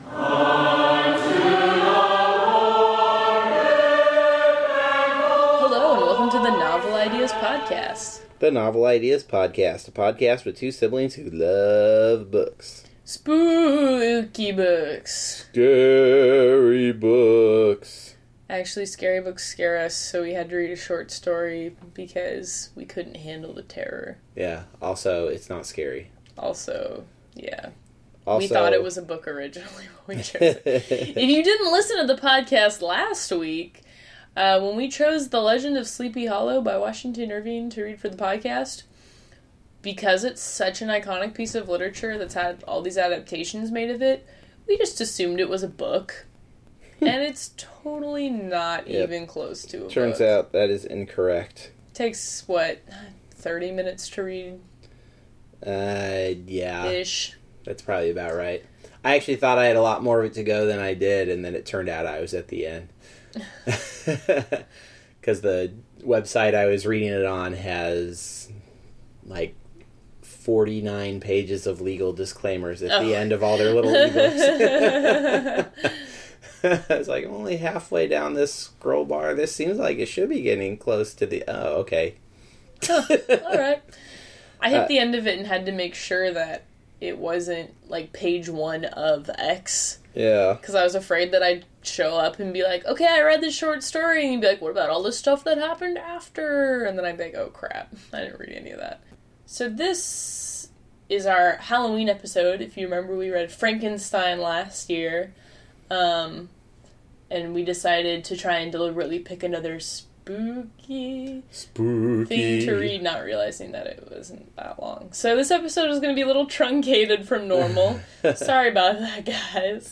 Listen to the episode to hear us discuss whether this is actually a horror story, adaptations of the story, general silliness, and why this story became a classic.